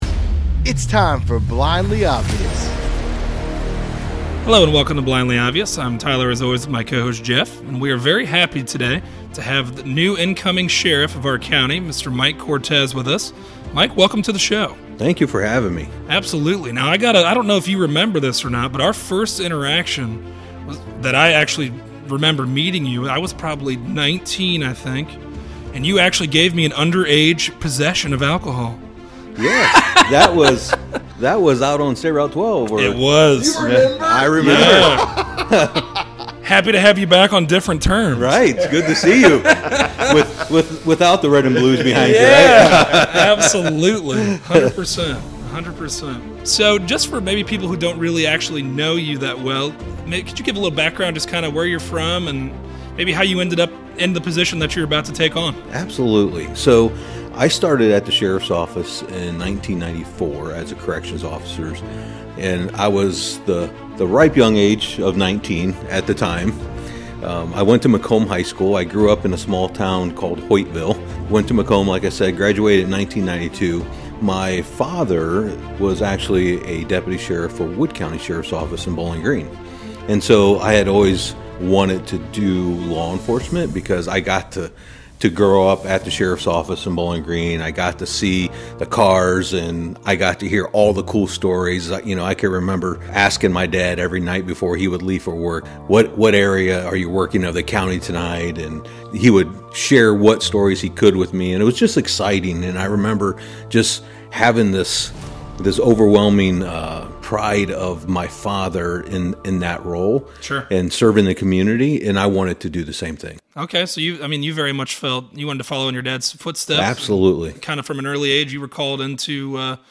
Sheriff Mike Cortez joins us to talk about his new position and the objectives he hopes to accomplish while in office.